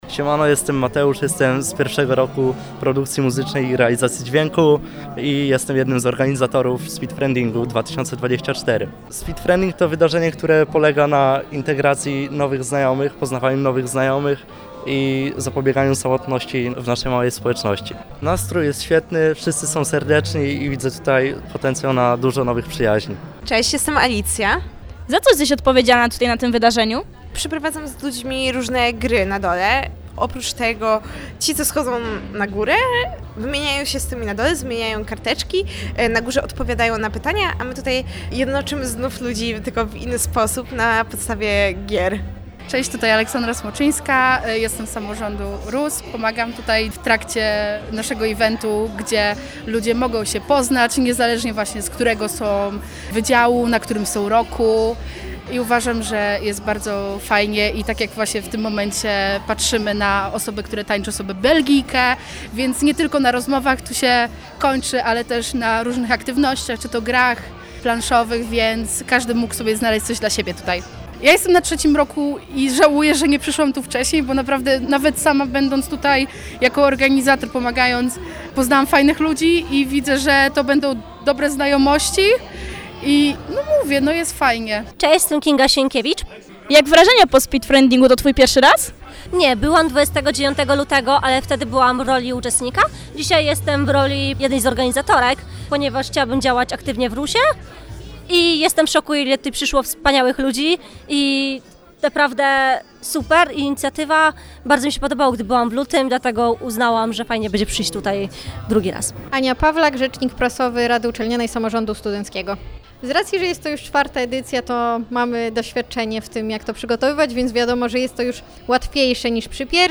Nasze reporterki rozmawiały także z osobami, które były zaangażowane w przygotowanie tego wydarzenia.